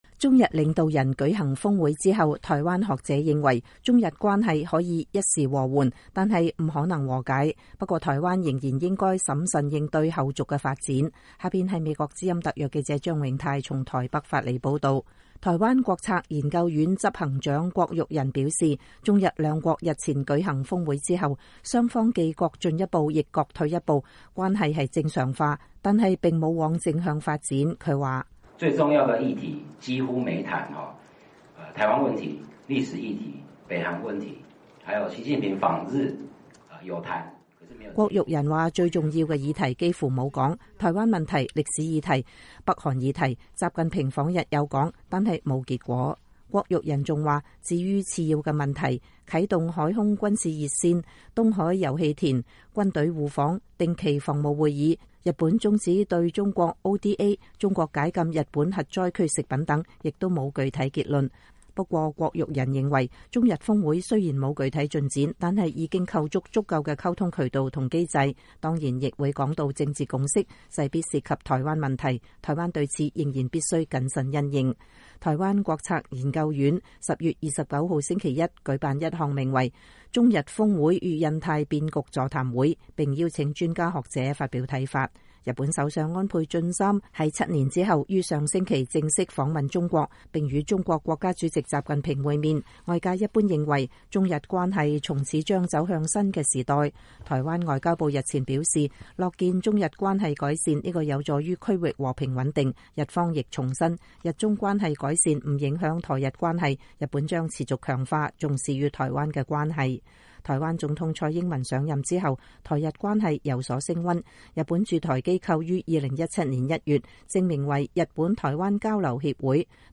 台灣國策研究院星期一(10月29號)舉辦一項名為“中日峰會與印太變局”座談會，並邀請專家學者發表看法。